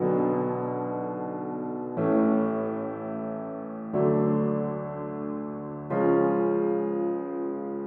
调子 122 BPM
Tag: 122 bpm Trap Loops Piano Loops 1.32 MB wav Key : Unknown